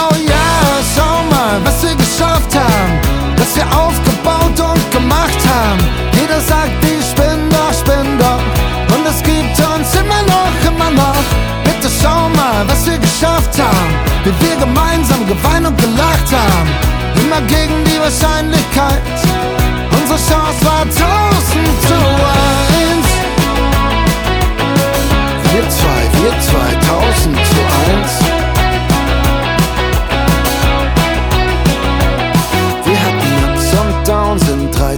2025-07-18 Жанр: Поп музыка Длительность